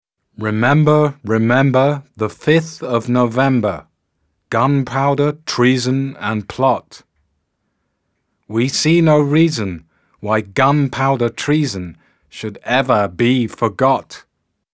Below, is a traditional English nursery rhyme written on an e-card (click on the rhyme if you want to hear it).